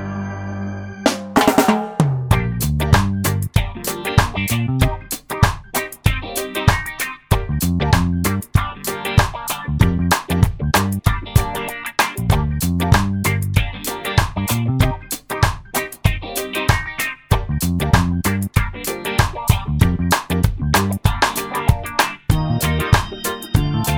no Backing Vocals Reggae 4:13 Buy £1.50